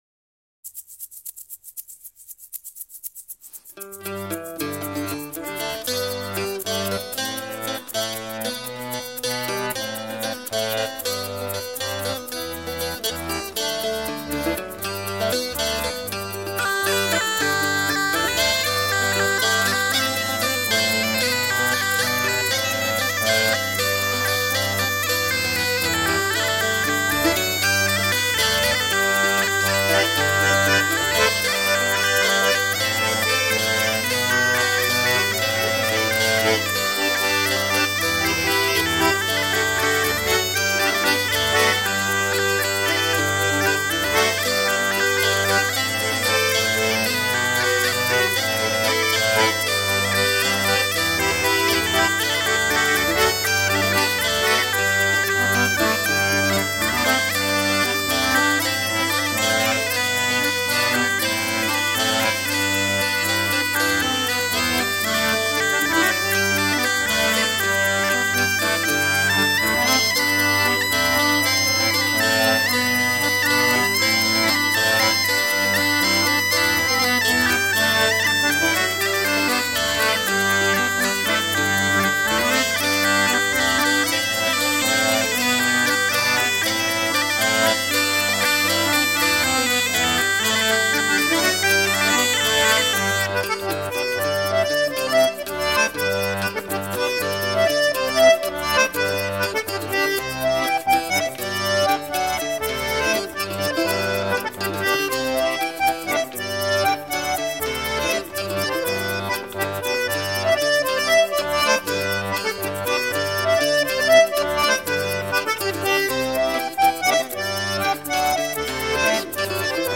Musiques à danser
accordéon diatonique, percus
guitare, bouzouki